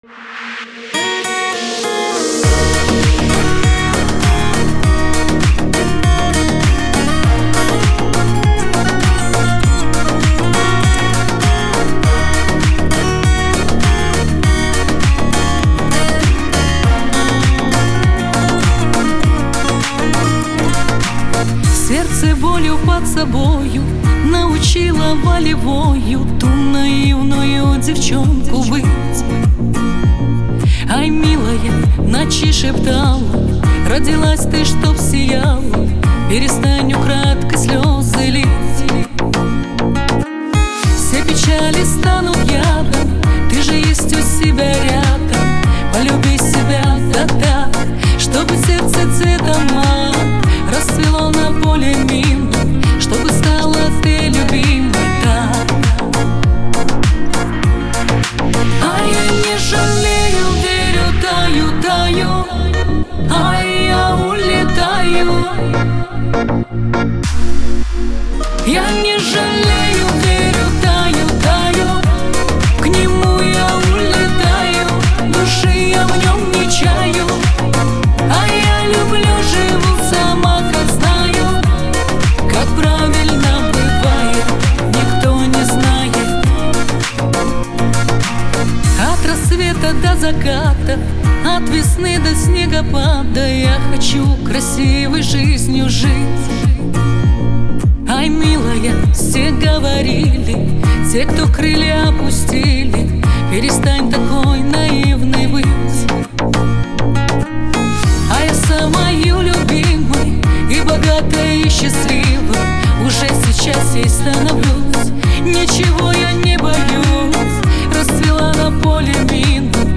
Лирика
pop